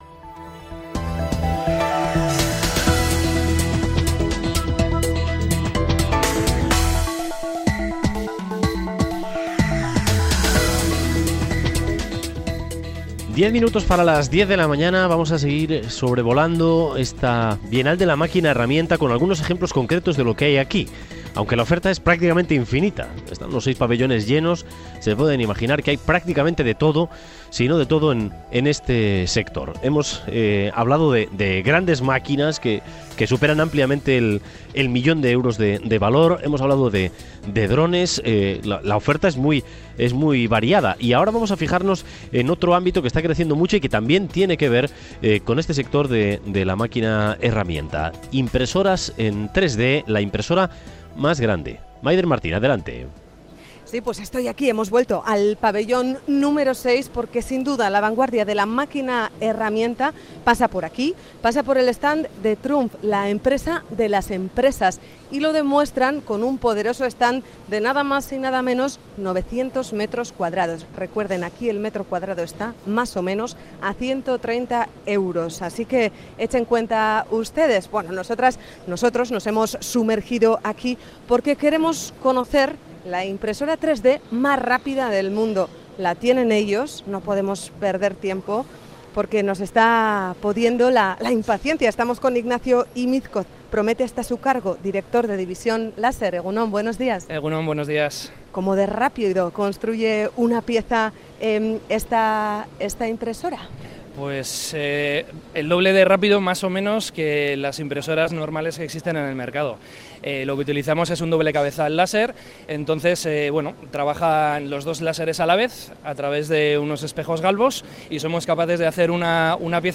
Audio: Trump tiene la impresora 3D más rápida del mercado y la muestra en la Bienal de la Máquina Herramienta. Nos acercamos a comprobar cómo funciona y también sus posibilidades. El precio: medio millón de euros.